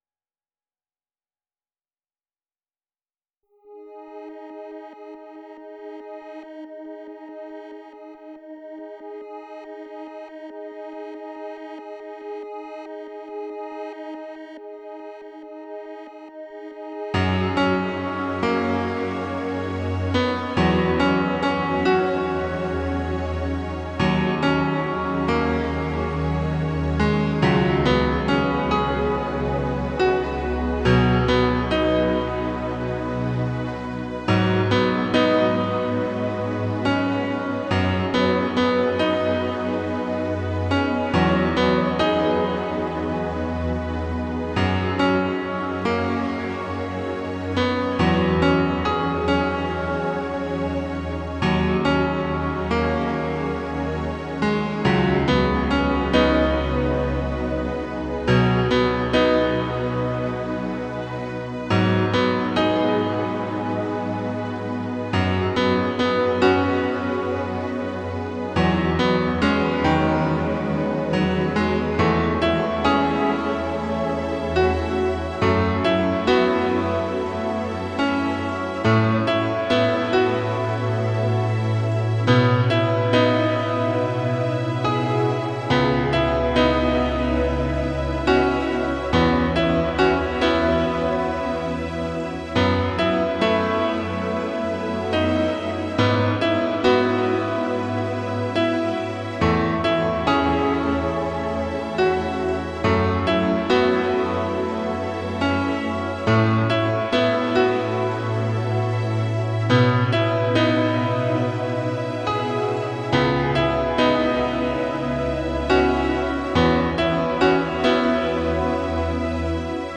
この時期はピアノの音に強弱がない。
当時としては厚く作ってある。
DAWに取り込んで再構築。音源はIK SampleTank 3。